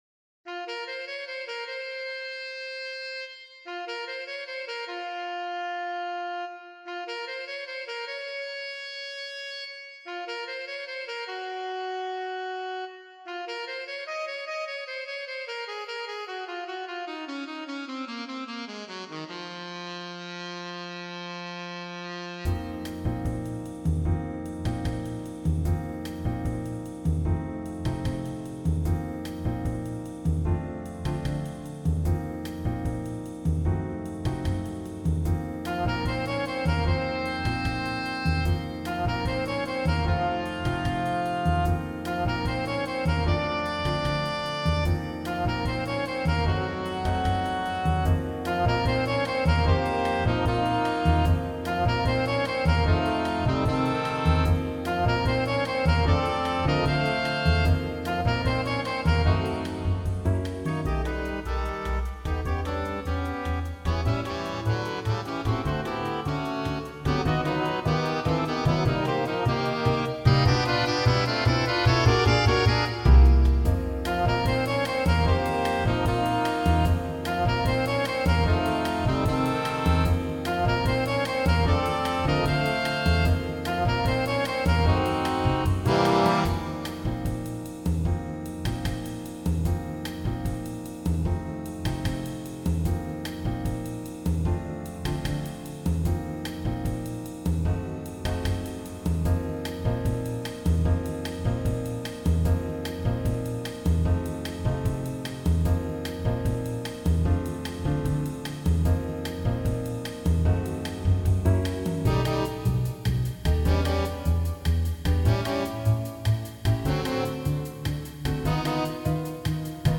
All audio files are computer-generated.